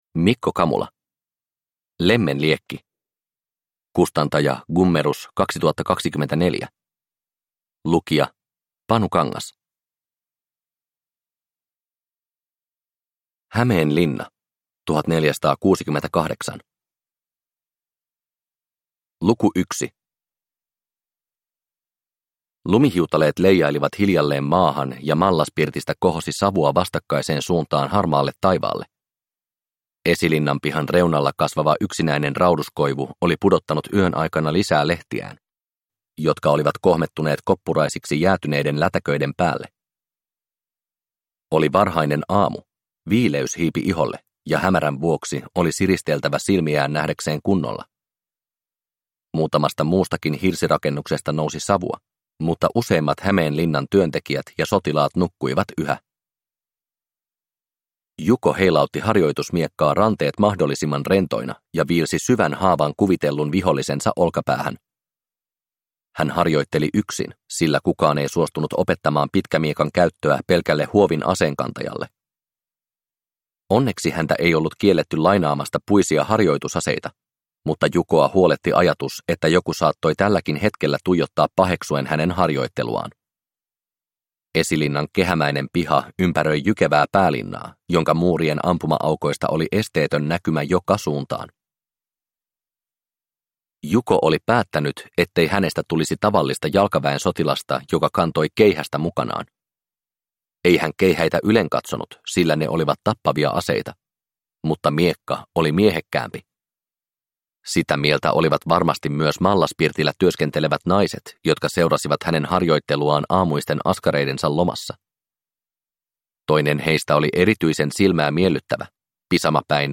Lemmenliekki – Ljudbok